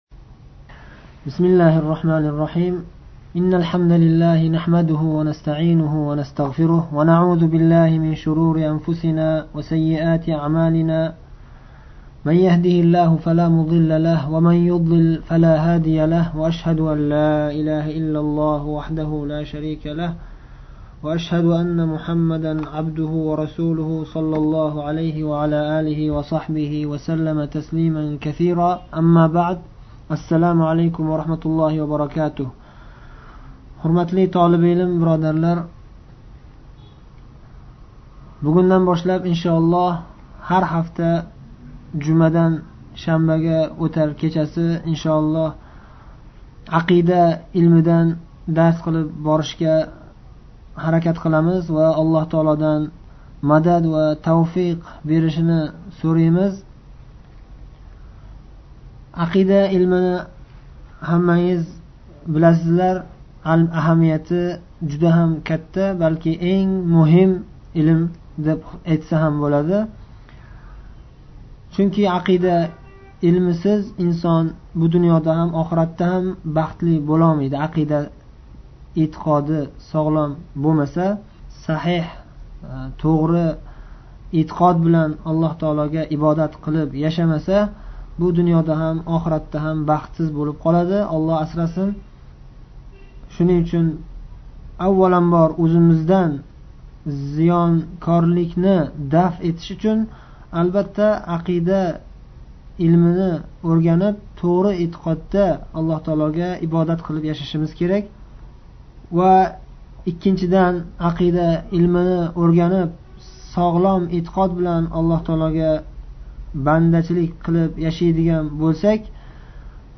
01-“Ақидаи Таҳовия” дарси: кириш сўзи (Қисқартирилган)